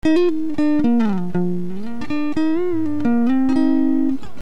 Clip1, unprocessed